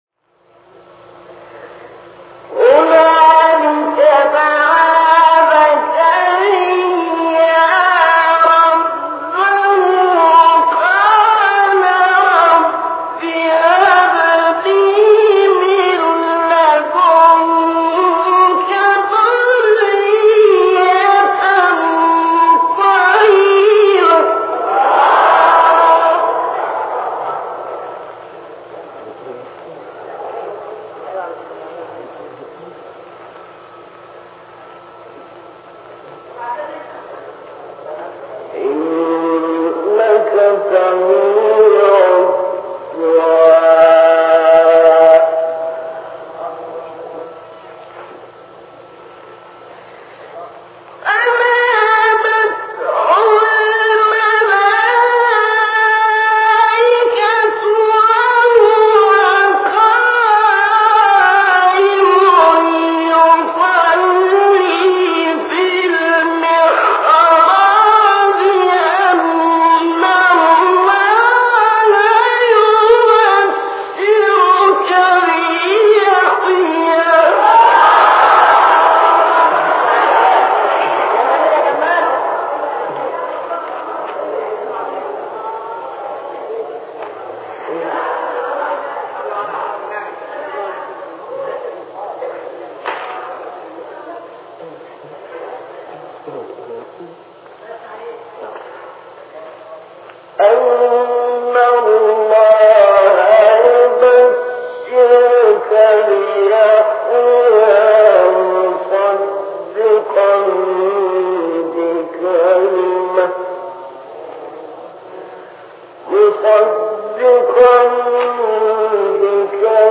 مقام رست استاد شعیشع | نغمات قرآن | دانلود تلاوت قرآن